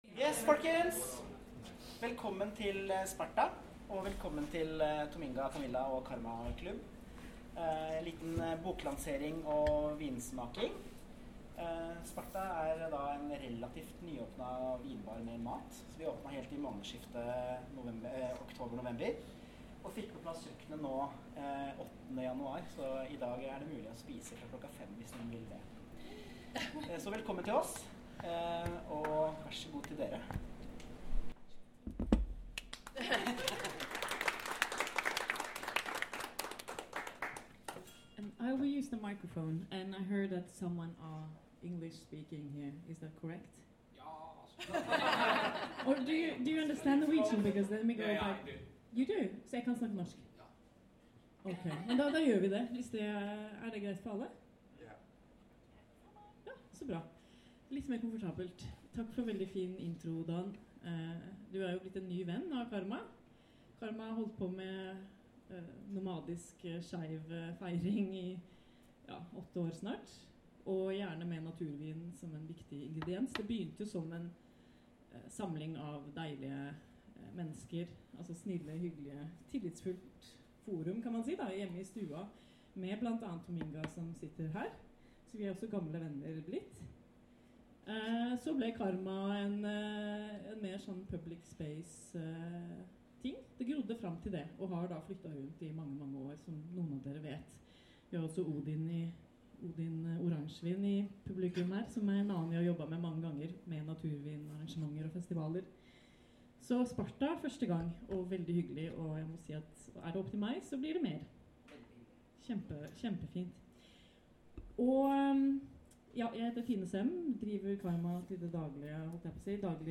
Karmaklubb* x Sparta: Book launch of ‘Natural Trailblazers’ and wine tasting
Thought & pleasure, Sparta, Oslo
Talk: Approx. 40 min.